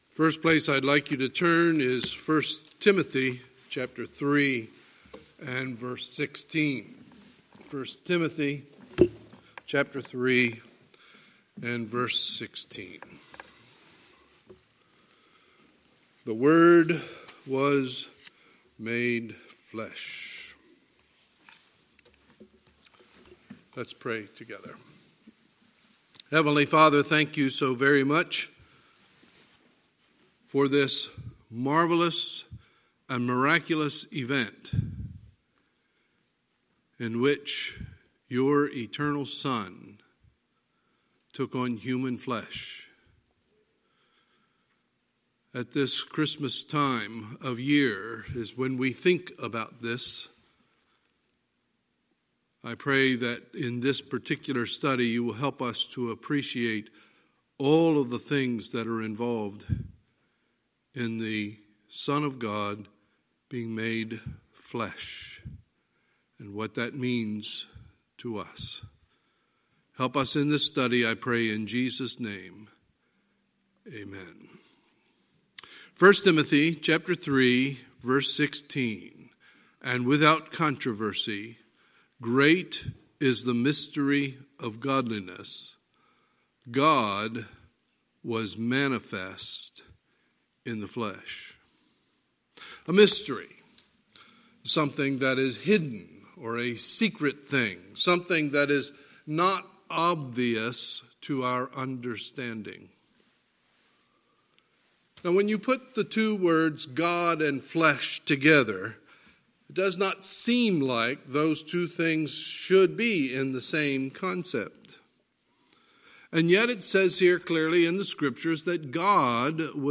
Sunday, December 15, 2019 – Sunday Morning Service